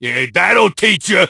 bull_kill_vo_03.ogg